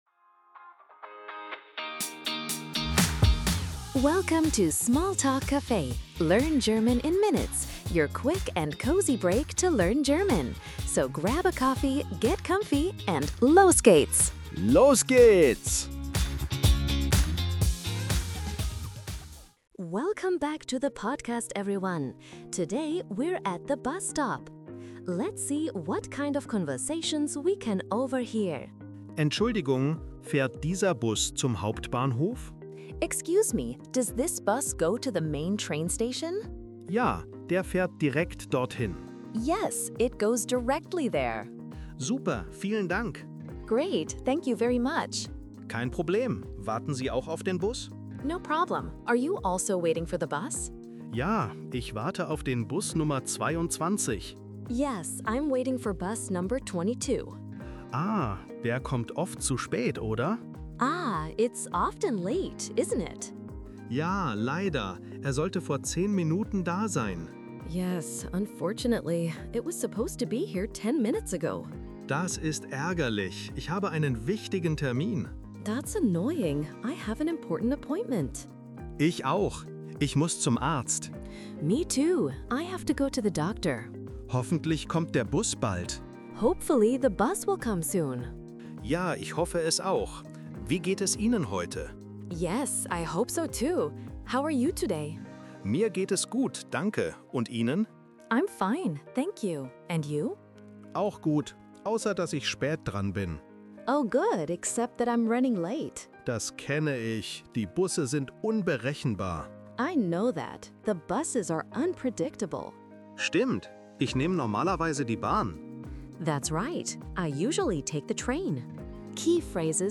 Each episode brings you bite-sized, everyday conversations you can use at the café, bakery, doctor’s office, or when you awkwardly bump into your neighbor.
You’ll get real dialogues, quick breakdowns, and fun tips in under 5 minutes.